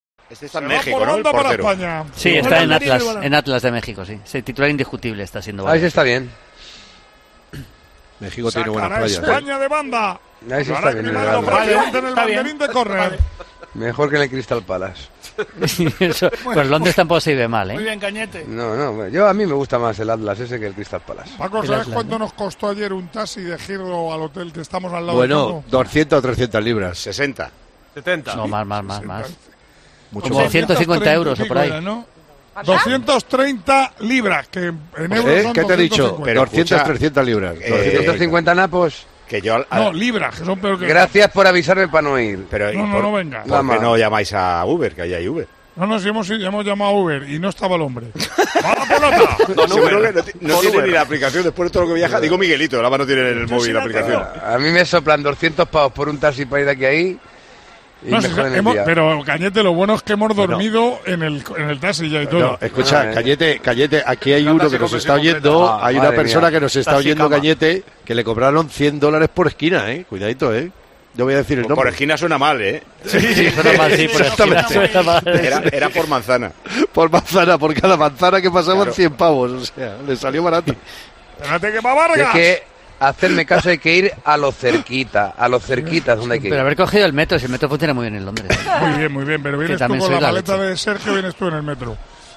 Narració, des de Londres, del partit amistós de futbol masculí entre les seleccions de Colòmbia i Espanya. Comentari sobre el preu del taxi londinenc.
Esportiu